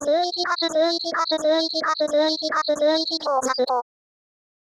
alarm_test.wav